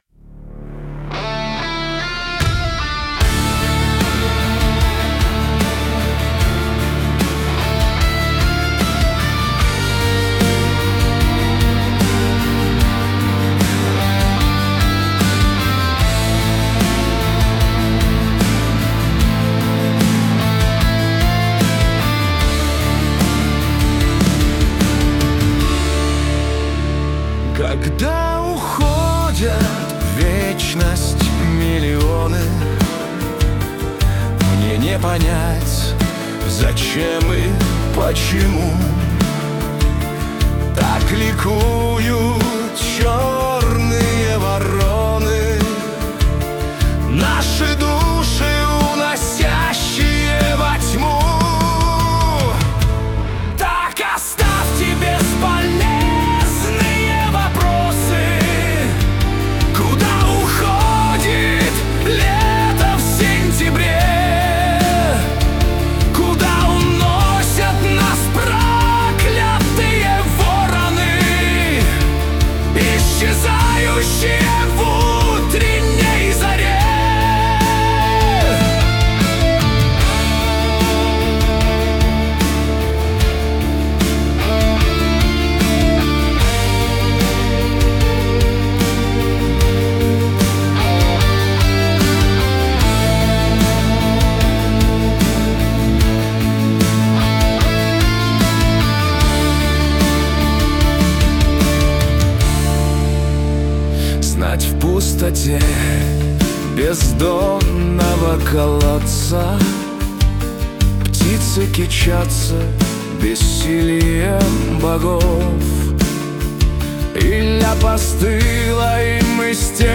Music and vocals generated via AI tools
Музыка и вокал сгенерированы с помощью искусственного интеллекта
• Исполняет: V4.5 Fusion
• Жанр: Рок